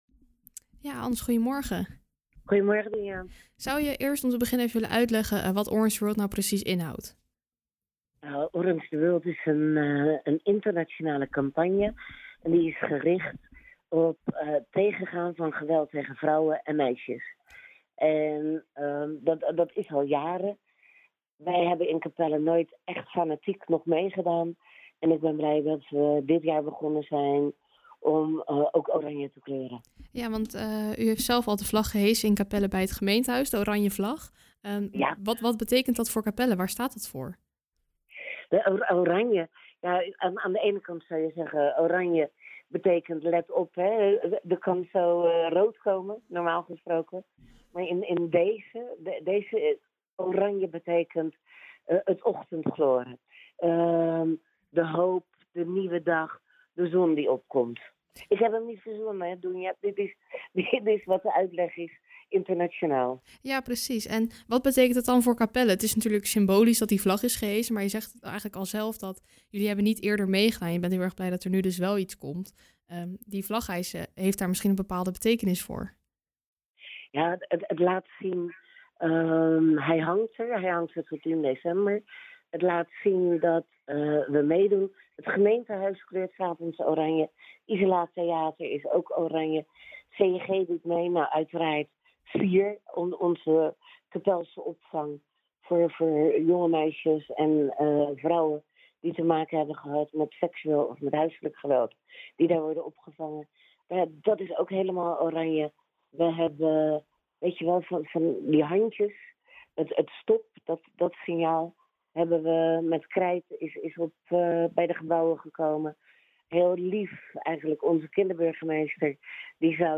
praat over het belang en de invulling hiervan in Capelle met wethouder Ans Hartnagel.